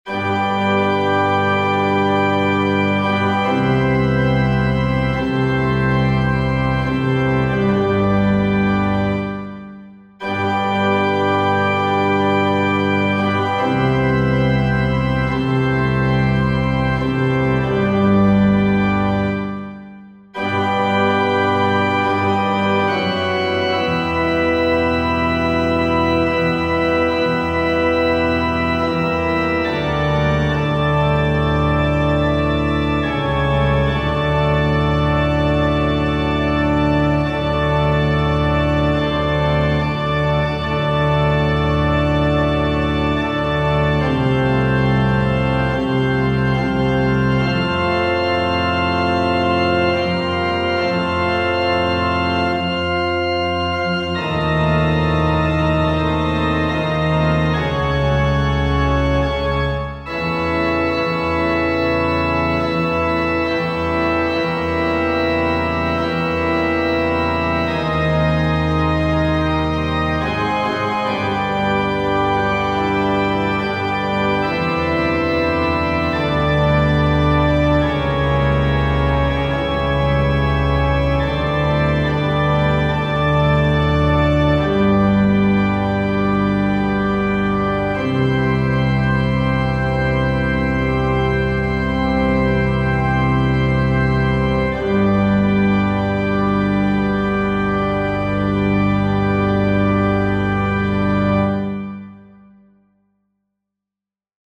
FF:HV_15b Collegium male choir
VeniSancte-Bas.mp3